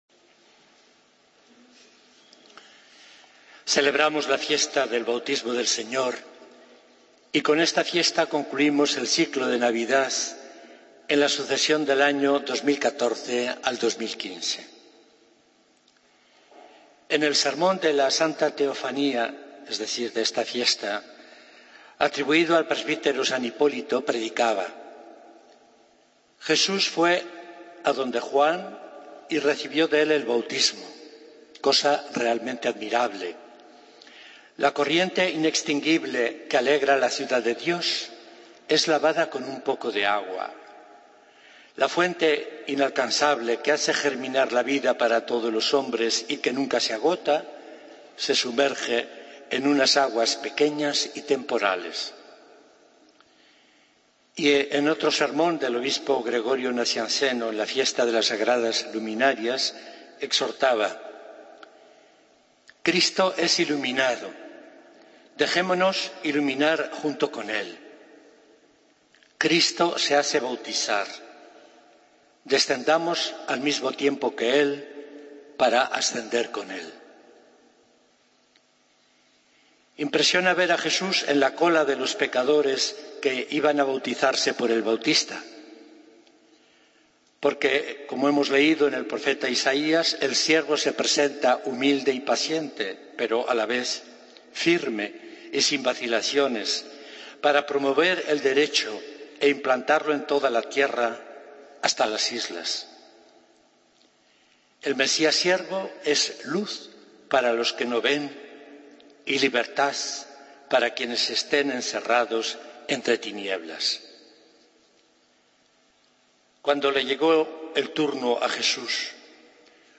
Homilía del 11 de Enero de 2015